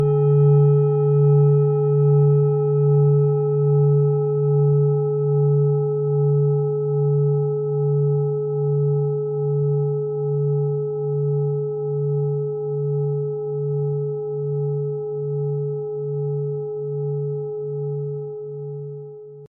• Mittlerer Ton: Mond
PlanetentöneMars & Mond
MaterialBronze